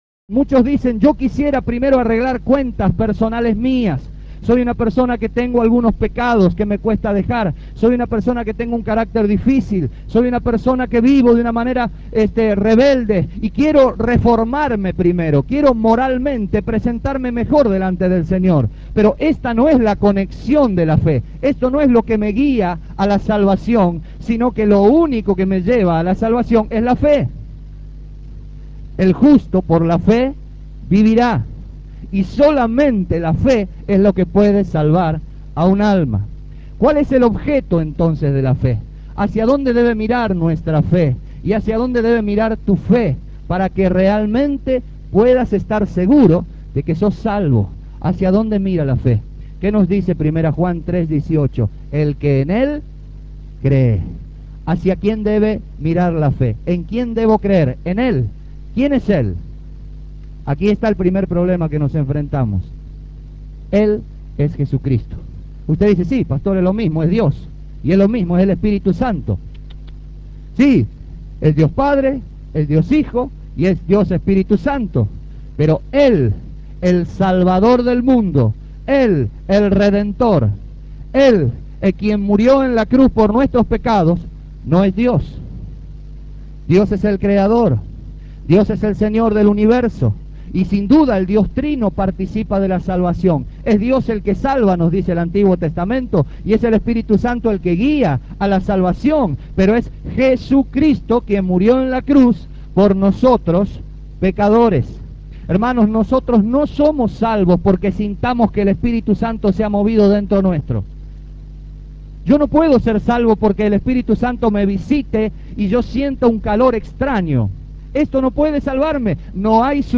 Serm�n